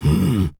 Male_Grunt_Hit_06.wav